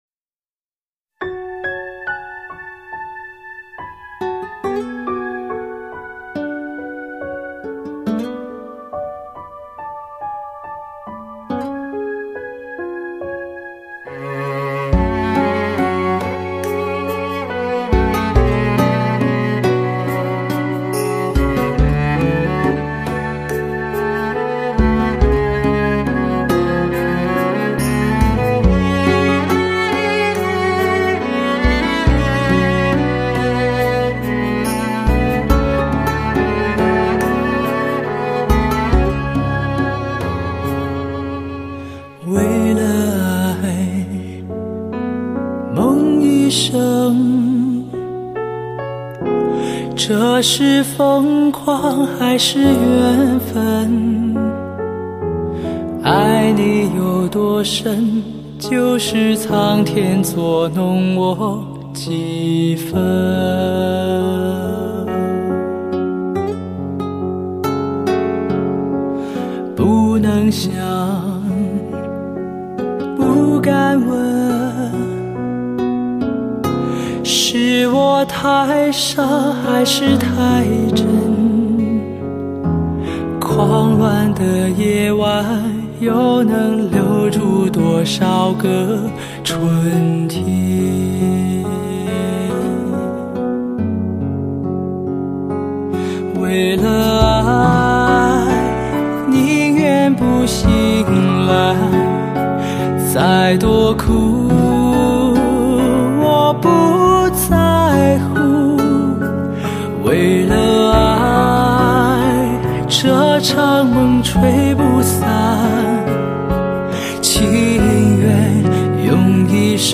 这是公司又一跨界录音的尝试。
本品的录音极为考究，
精致的录音让歌手具有电子管时代录音的温润特性,
中频感染力使任何人都难以抵挡，
而准确的定位更让人回味无穷。